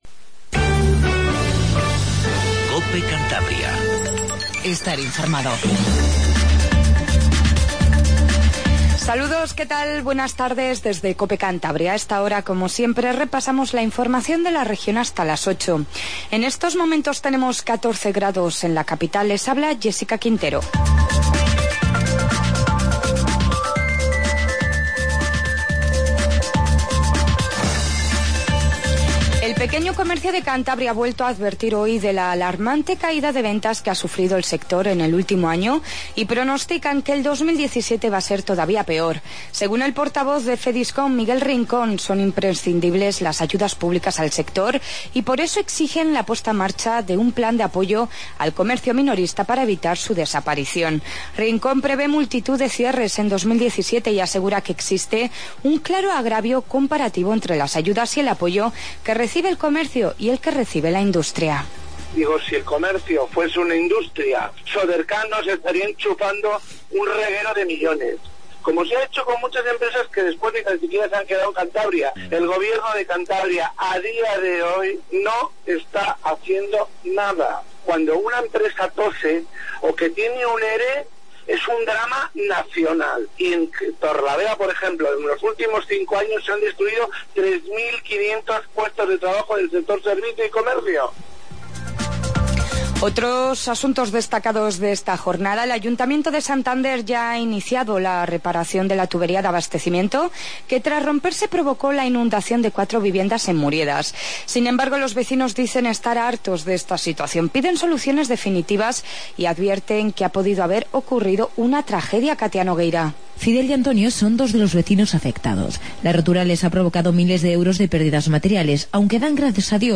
INFORMATIVO DE TARDE